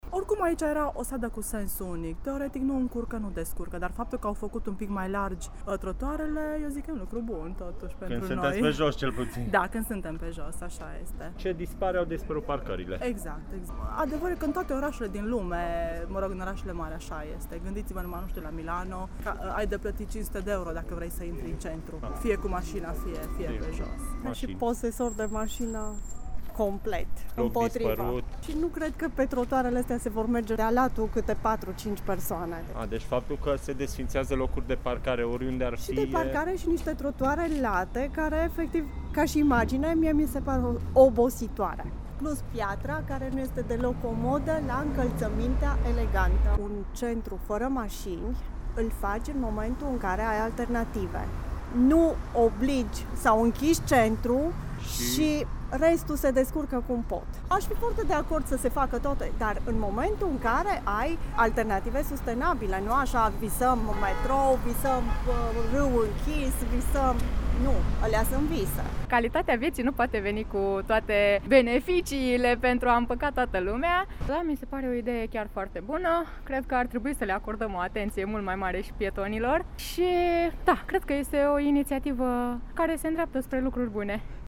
Câteva persoane intervievate în zonă ilustrează, în înregistrarea AUDIO de mai jos, argumentele pro și contra vizavi de intervențiile în desfășurare.